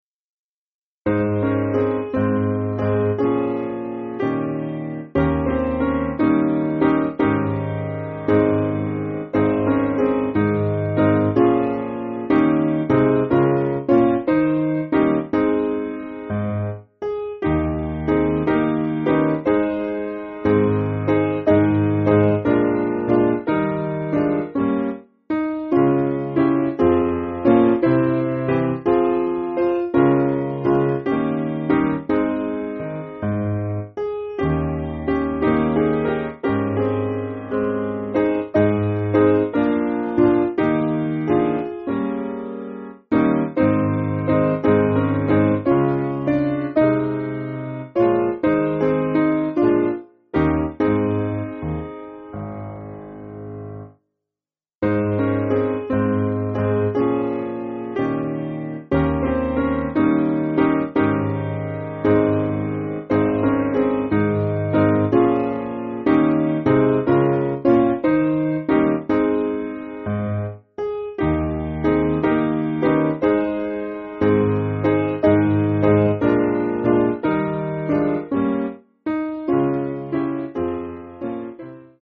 Simple Piano
3/Ab